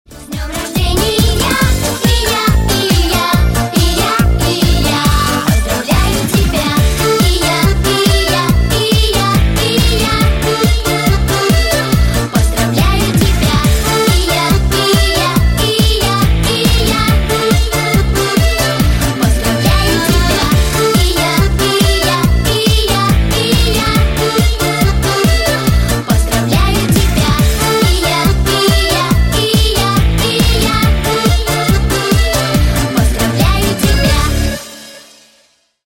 Весёлые Рингтоны
Танцевальные Рингтоны